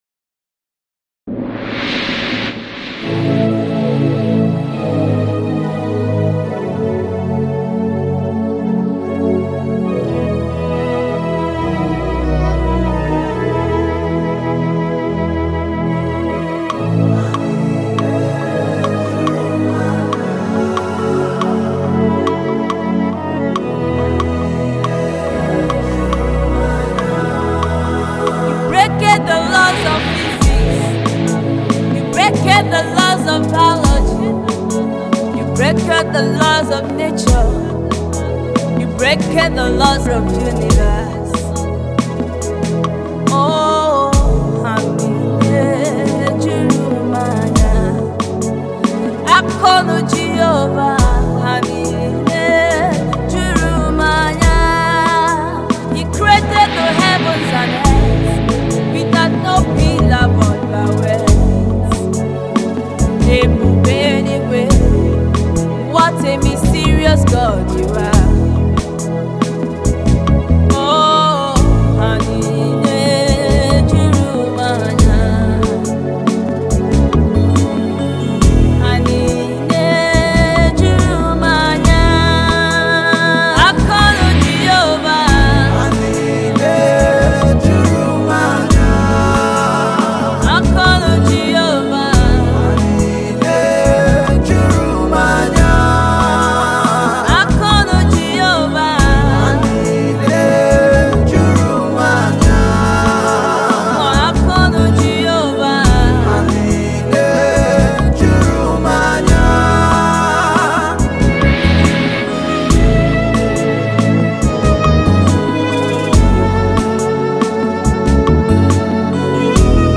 a melodic voice